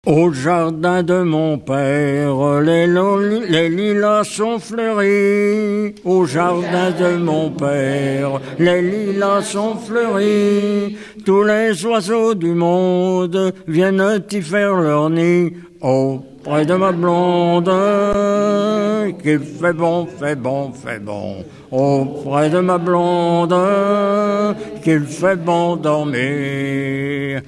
Genre laisse
Enquête Arexcpo en Vendée-C.C. Talmont
Pièce musicale inédite